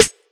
tyru_snr.wav